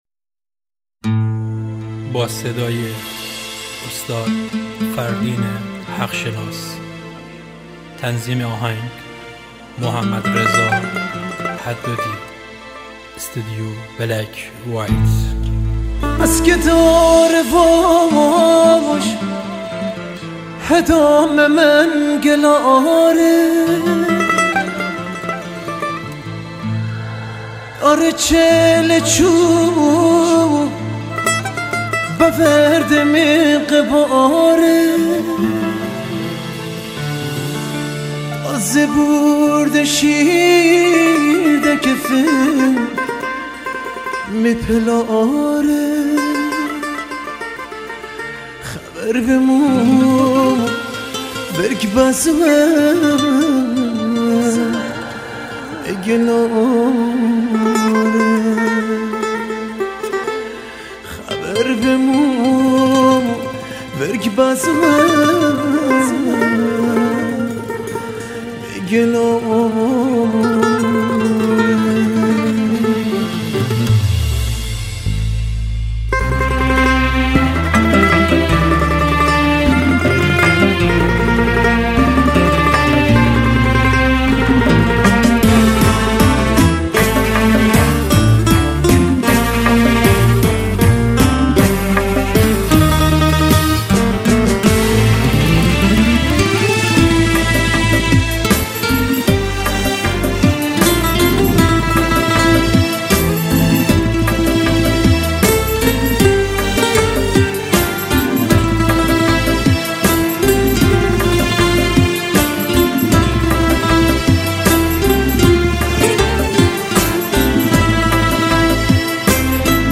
موضوع : آهنگ غمگین , خوانندگان مازنی ,